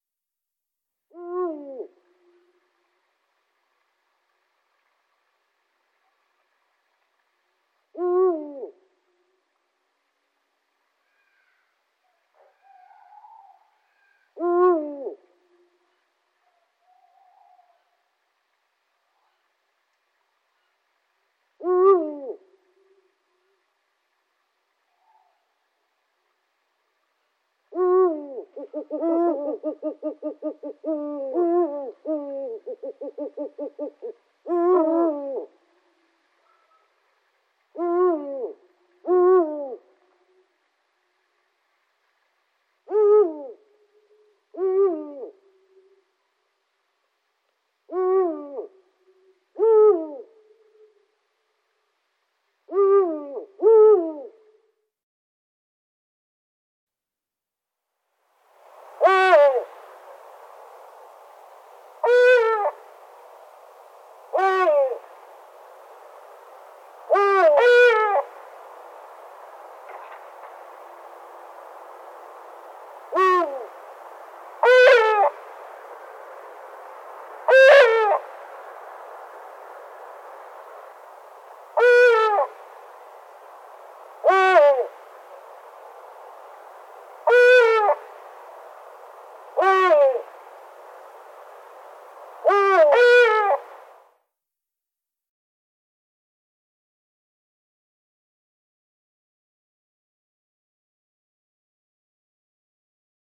Oehoe
Het mannetje heeft een zware stem en roept: “hoé-oe….“. Het vrouwtje roept zachter en wat hoger.
oehoezang.mp3